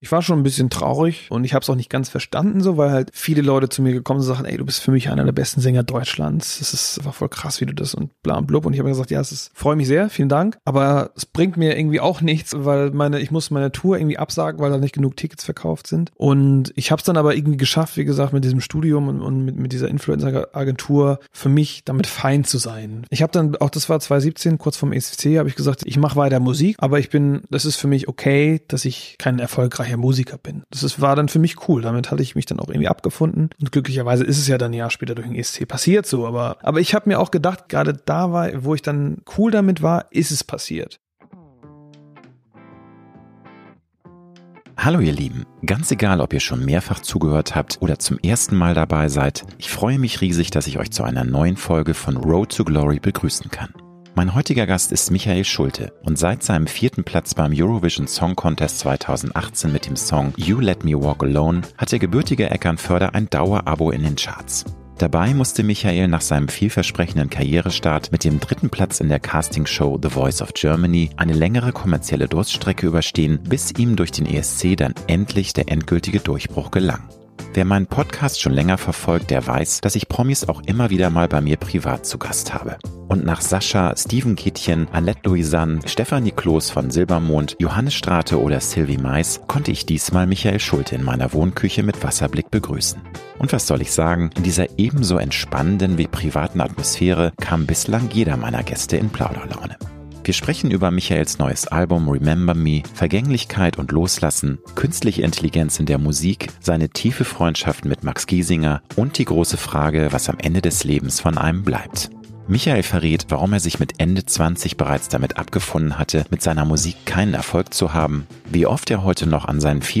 Und nach Sasha, Steven Gätjen, Annett Louisan, Stefanie Kloß von „Silbermond“, Johannes Strate oder Sylvie Meis konnte ich diesmal Michael Schulte in meiner Wohnküche mit entspannendem Wasserblick begrüßen.